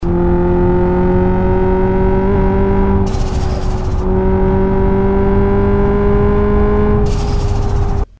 Play a custom blow off valve (BOV) sound ? lol like a wav, then I can delete the .raw file and hear an actual 1 that doesnt loop all funny.
Hear when I let off the gas it played the file but as soon as i stepped on the gas again it stopped the BOV.